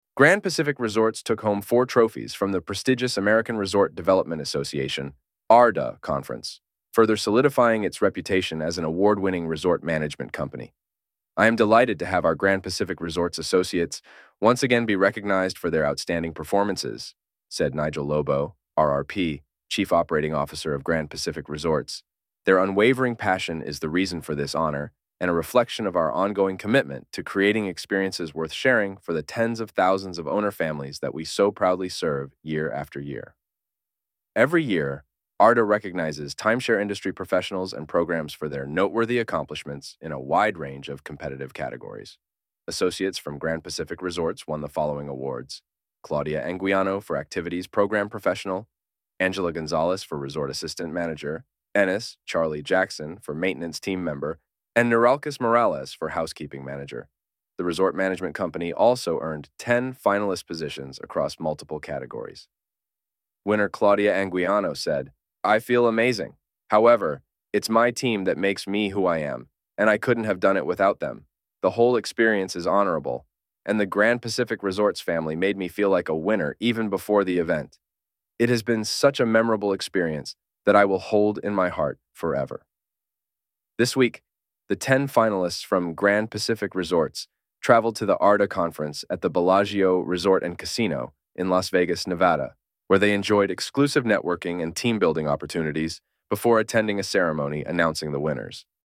ARDA-Awards-AI-Voiceover.mp3